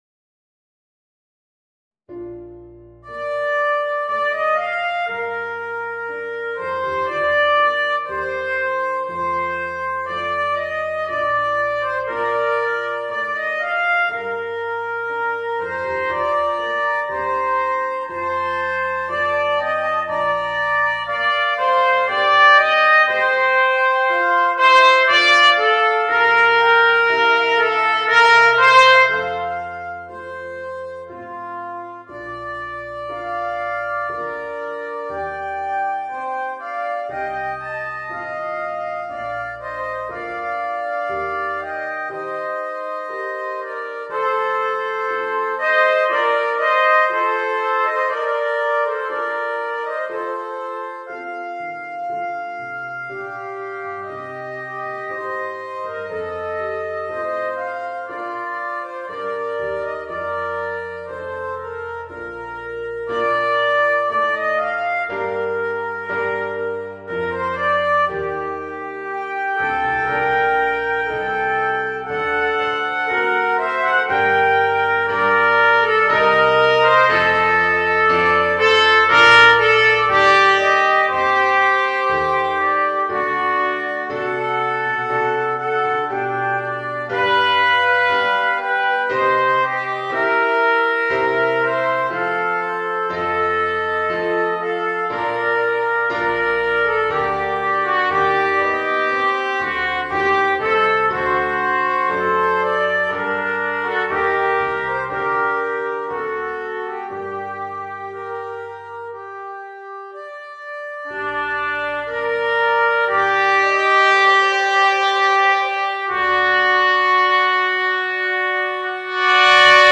Voicing: 2 Eb Cornets and Piano